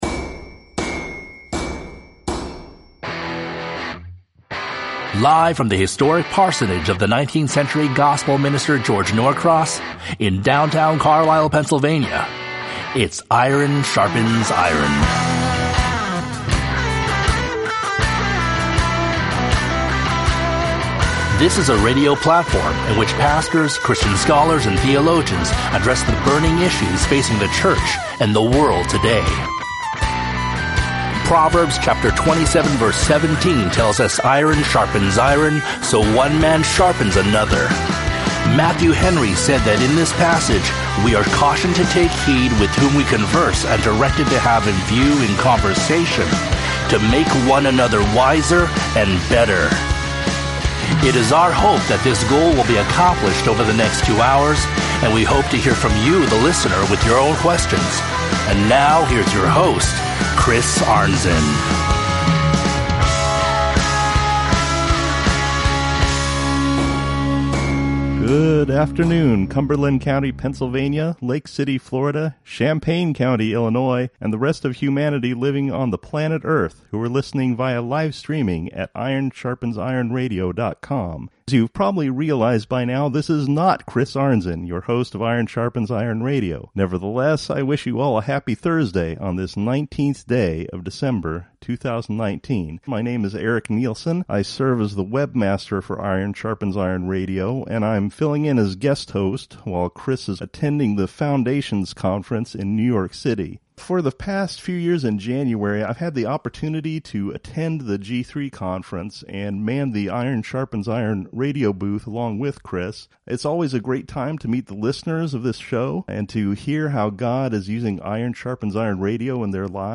Interviews recorded LIVE at the 2019 G3 CONFERENCE!!!